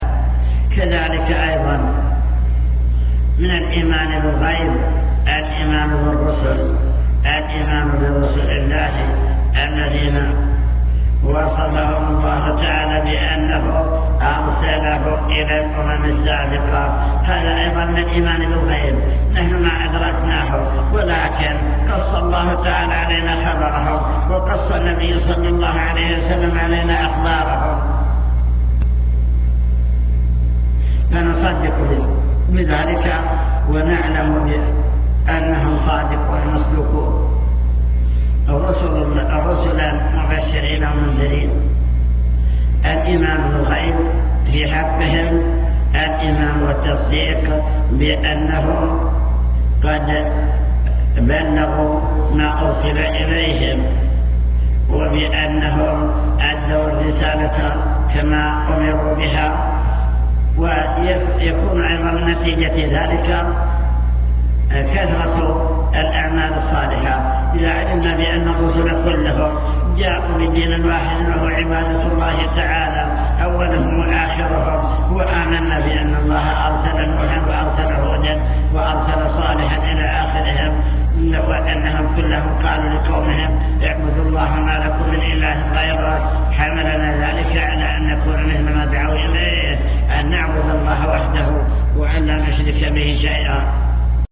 المكتبة الصوتية  تسجيلات - محاضرات ودروس  محاضرة الإيمان باليوم الآخر أمور غيبية يجب الإيمان بها